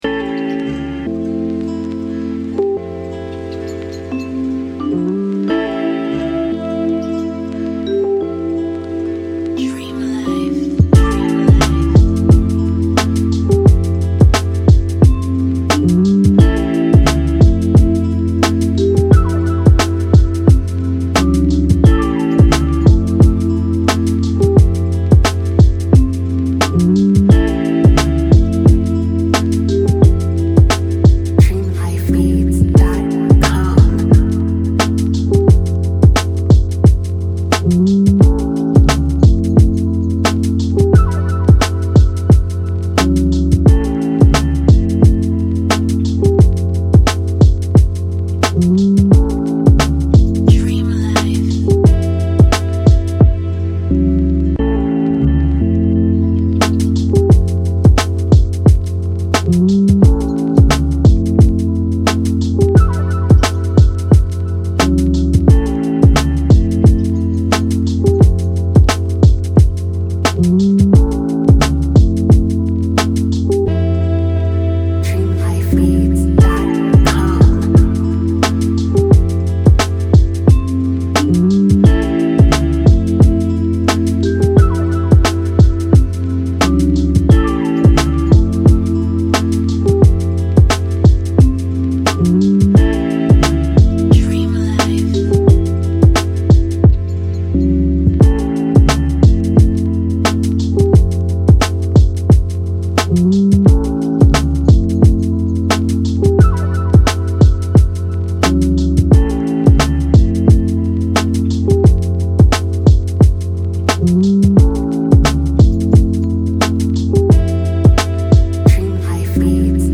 R&B
A Minor